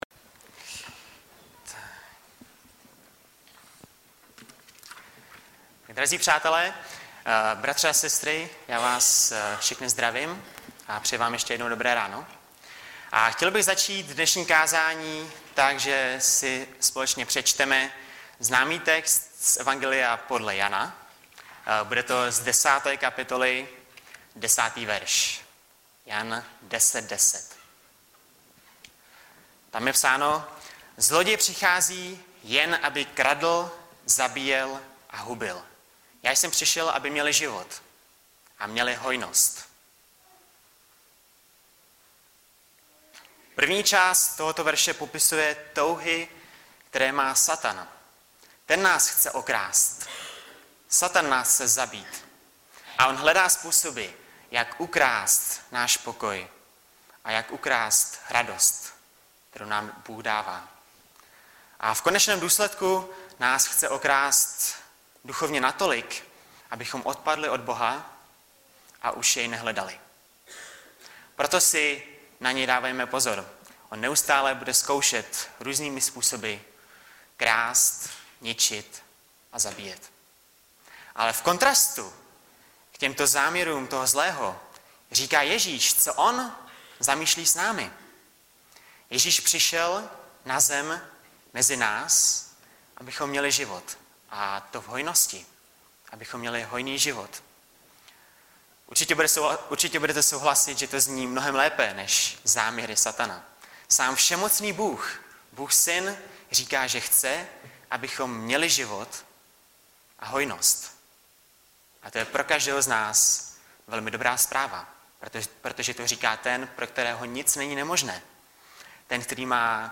Webové stránky Sboru Bratrské jednoty v Litoměřicích.
Hlavní nabídka Kázání Chvály Kalendář Knihovna Kontakt Pro přihlášené O nás Partneři Zpravodaj Přihlásit se Zavřít Jméno Heslo Pamatuj si mě  02.03.2014 - MÁŠ HOJNOST?